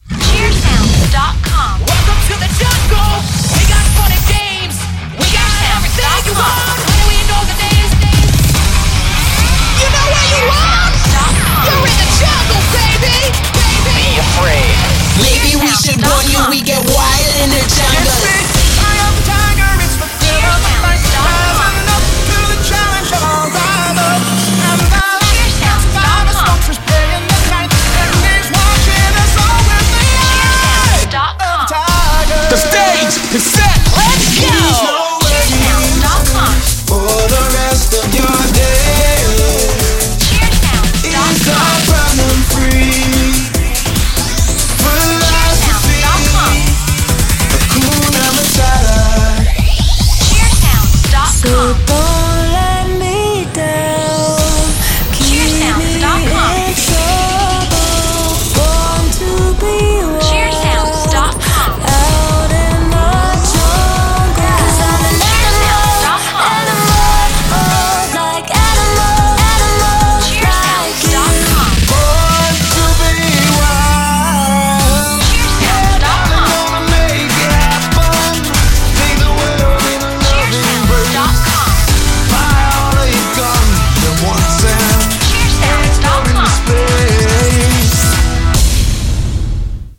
Premade Cheer Music Mix